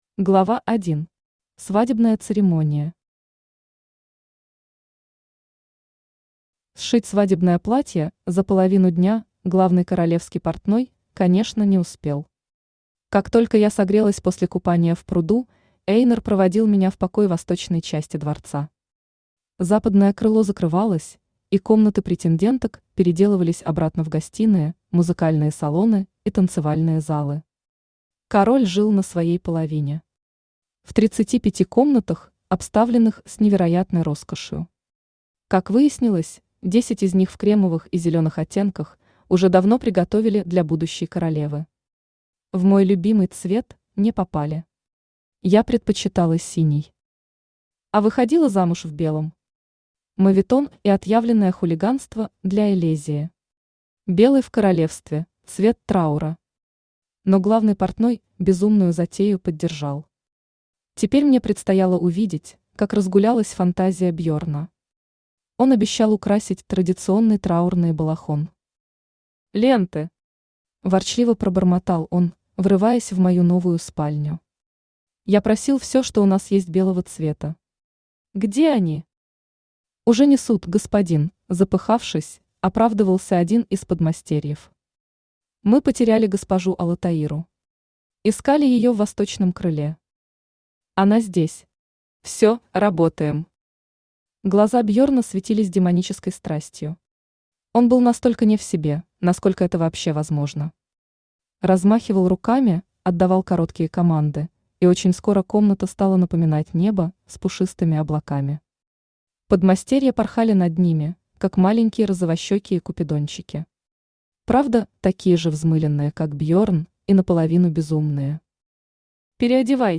Аудиокнига Замуж за дракона. Свадьба | Библиотека аудиокниг
Aудиокнига Замуж за дракона. Свадьба Автор Ирина Риман Читает аудиокнигу Авточтец ЛитРес.